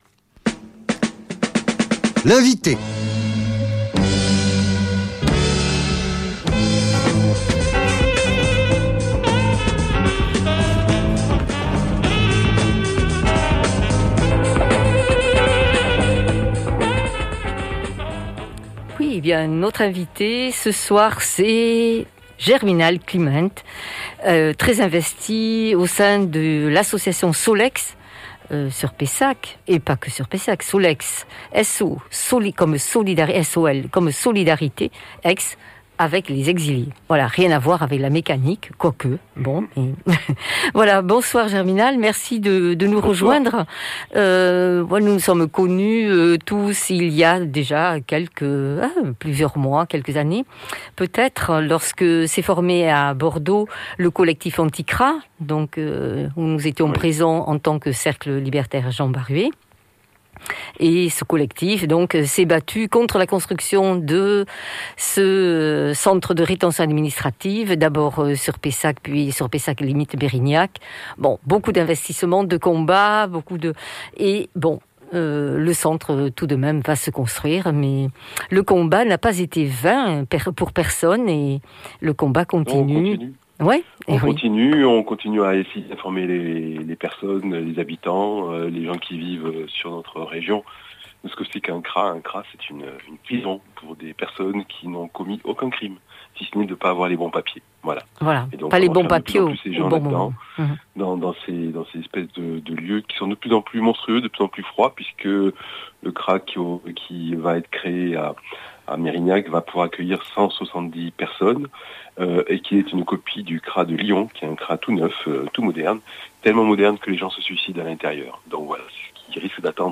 Interviews avec l’équipe de la Librairie du Muguet organisatrice de la FLLEM